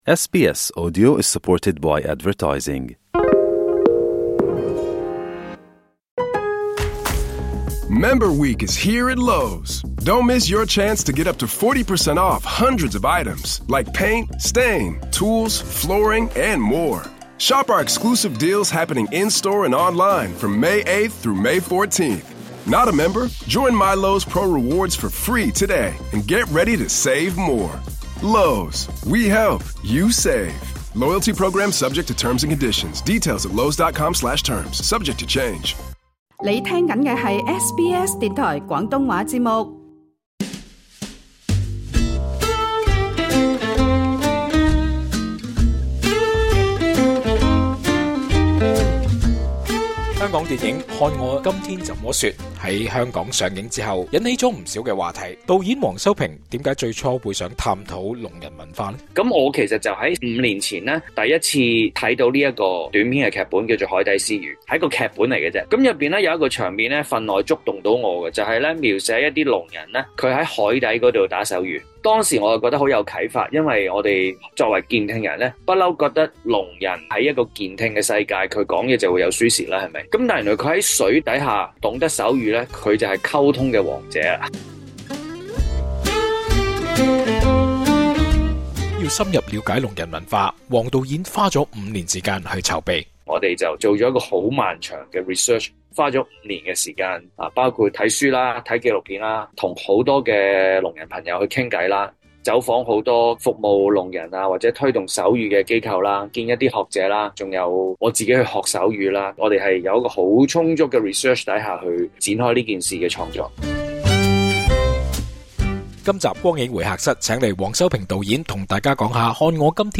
電影目前已在澳洲公映，而導演黃修平在接受本台【光影會客室】訪問時，就分享電影的靈感來源其實是來自另一條短片。